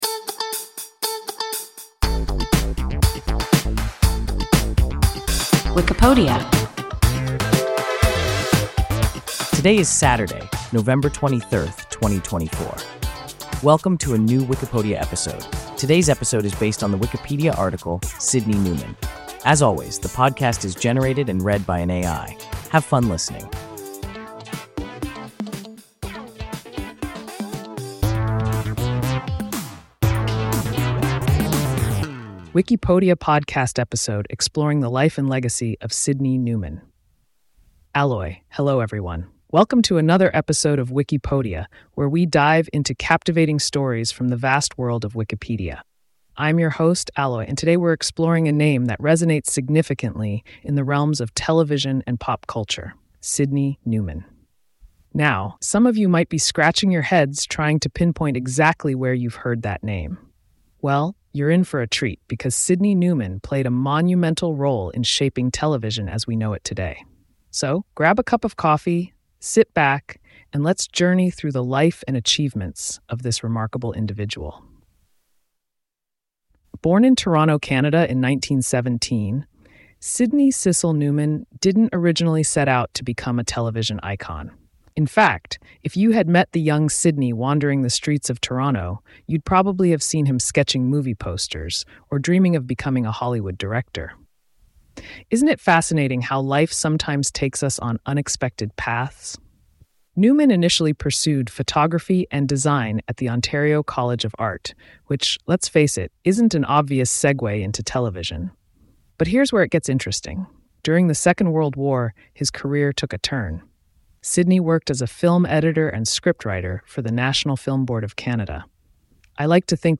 Sydney Newman – WIKIPODIA – ein KI Podcast